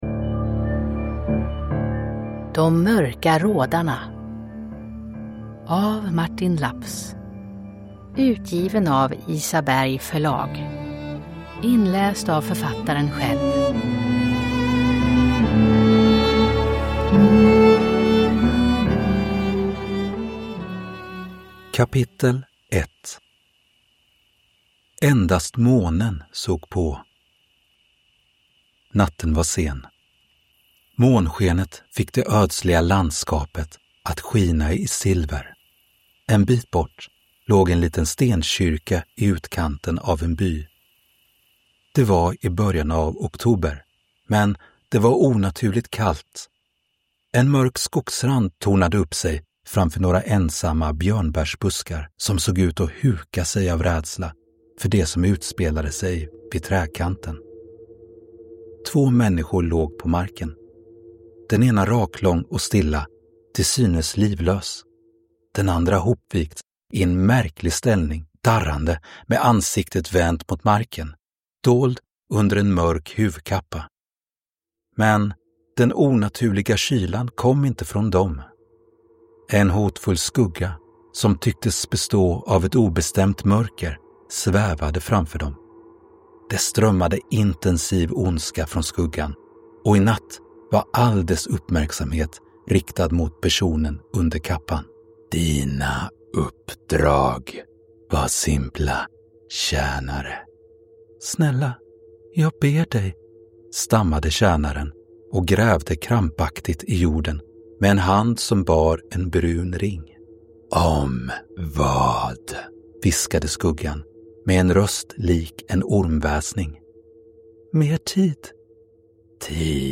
De mörka rådarna – Ljudbok
Följ med på ett nagelbitande äventyr fyllt av isande skrik i natten, mystiska glömda äppellundar och pulserande musikrytmer – där en tusenårig hemlighet väntar på att bli avslöjad.
I De mörka rådarna möts litteratur och musik och slingrar sig samman till en unik berättarform.